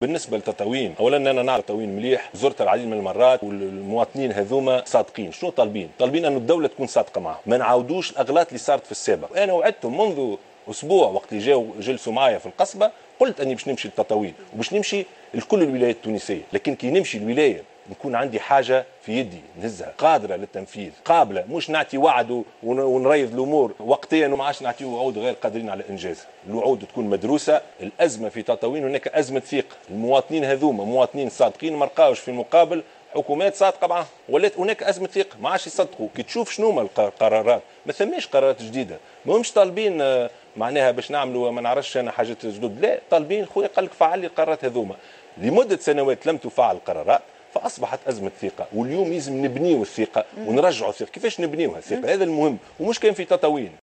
قال رئيس الحكومة يوسف الشاهد خلال حوار بث مساء اليوم على قناة الوطنية الأولى، إن الاحتجاجات الاجتماعية مشروعة في ظل الديمقراطية التي تعيشها البلاد، طالما أنها لم تمس من الأمن القومي وآلة الإنتاج.